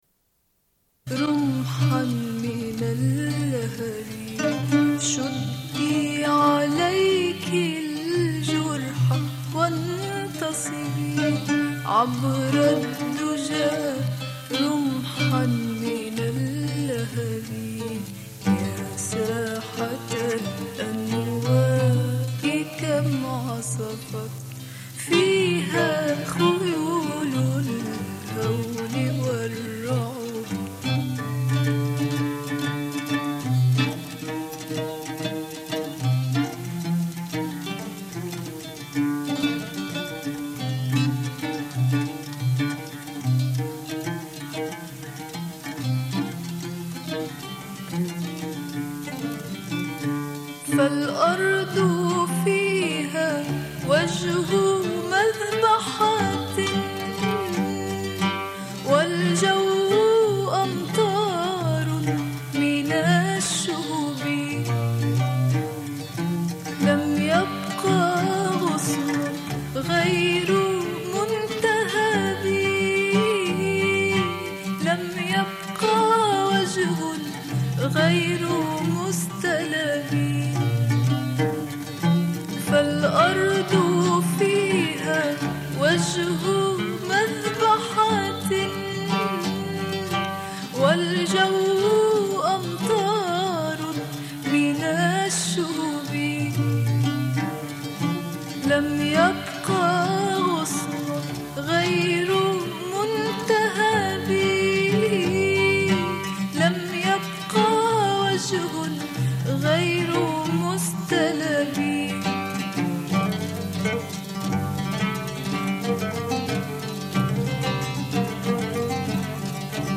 Une cassette audio, face B00:29:13